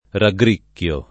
DOP: Dizionario di Ortografia e Pronunzia della lingua italiana